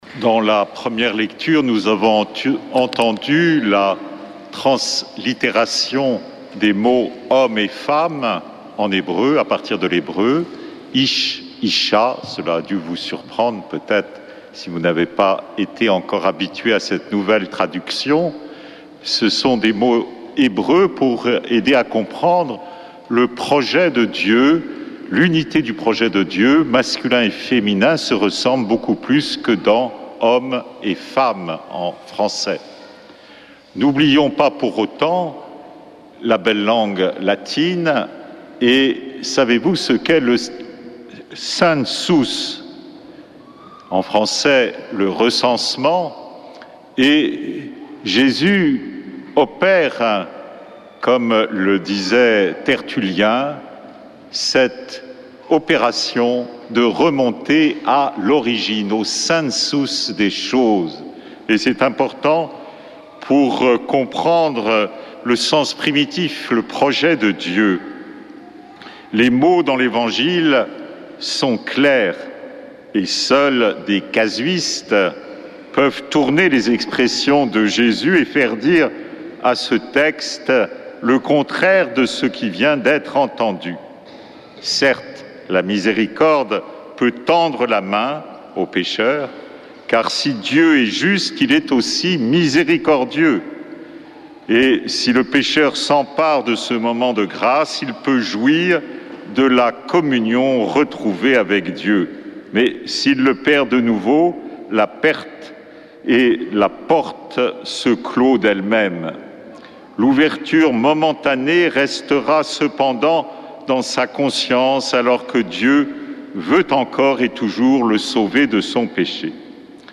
dimanche 6 octobre 2024 Messe depuis le couvent des Dominicains de Toulouse Durée 01 h 30 min
Homélie du 6 octobre